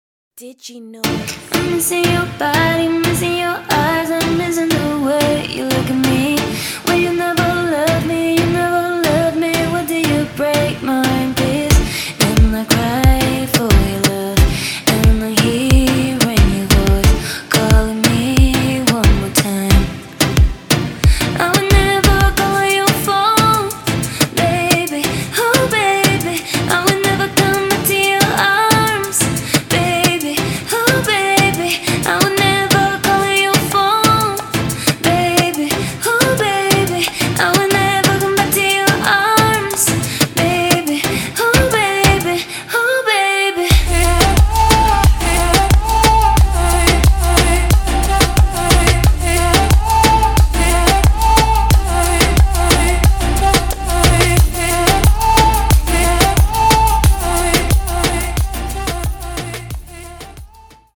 这些循环适合播放在电台上，具有轻松愉快的夏日气息。